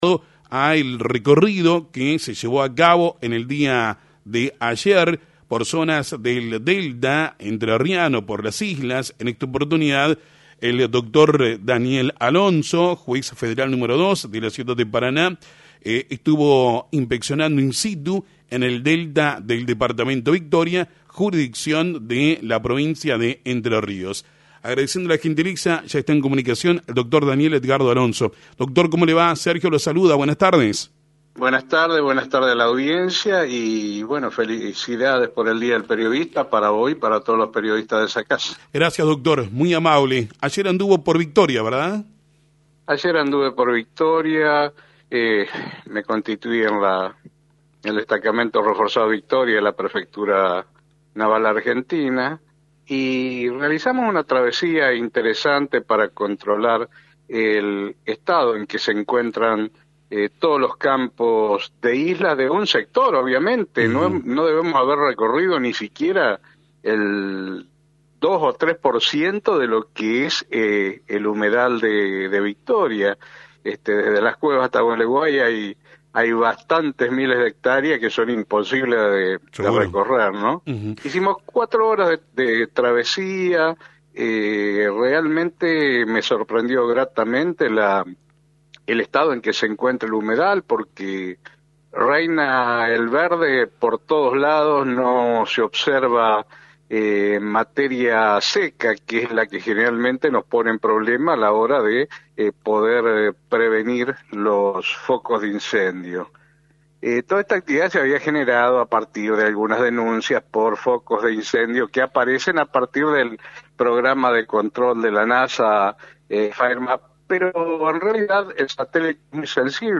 Daniel-Alonso-Juez-Federal.mp3